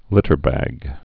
(lĭtər-băg)